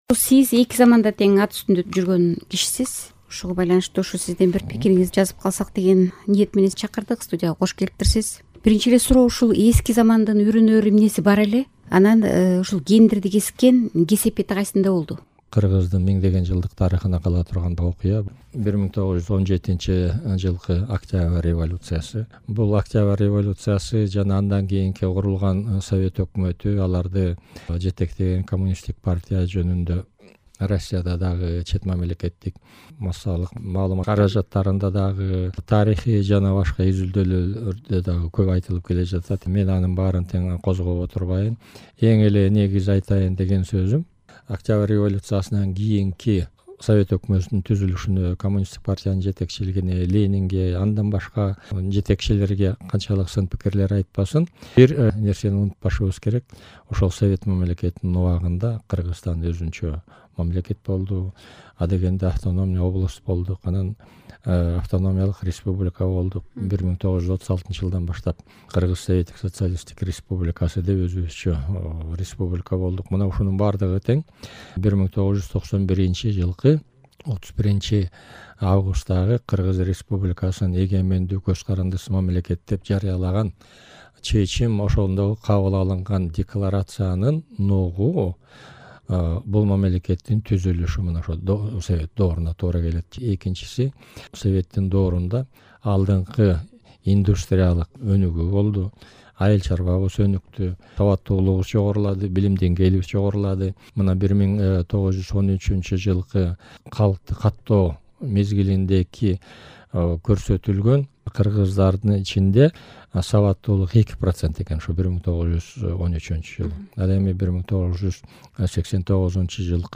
Медеткан Шеримкулов менен маек